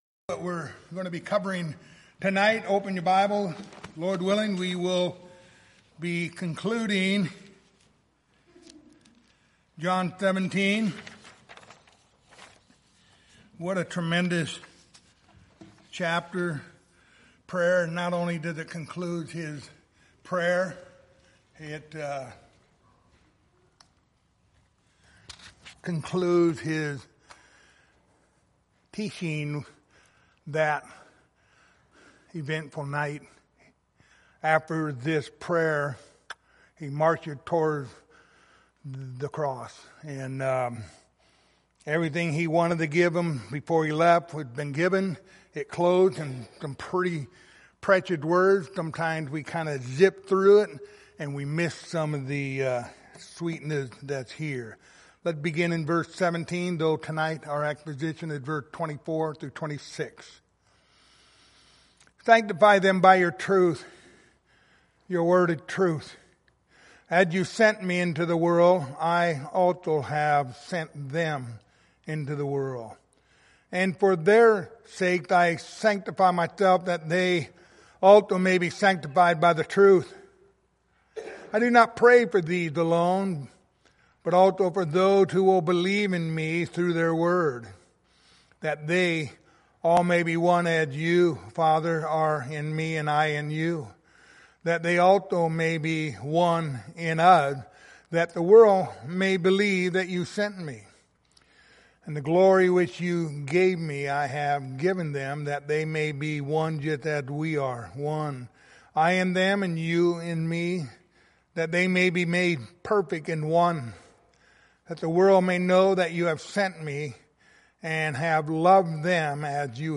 Passage: John 17:17-26 Service Type: Wednesday Evening